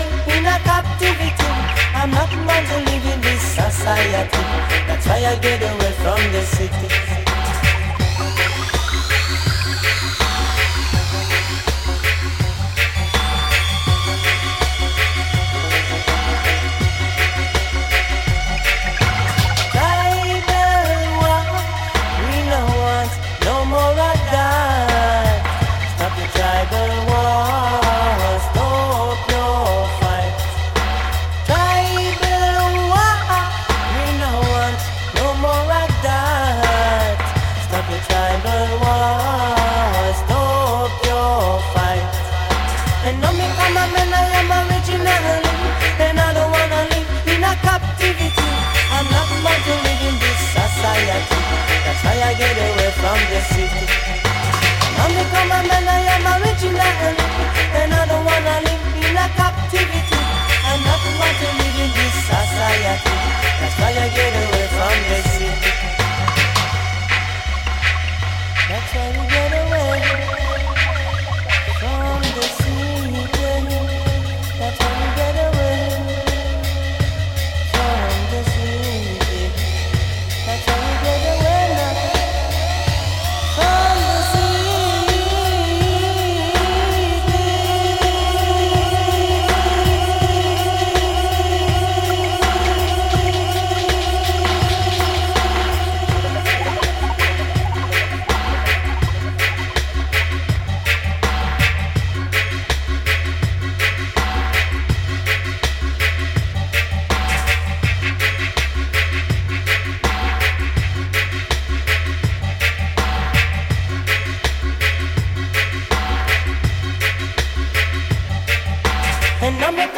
物悲しくもキャッチーなアラビック音階メロディ